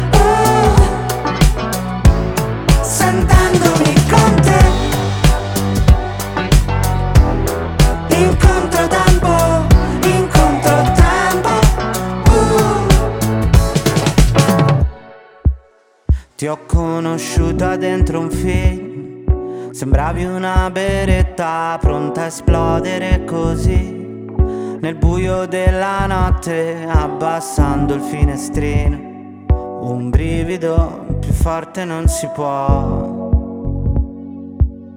Жанр: Поп / Инди
# Indie Pop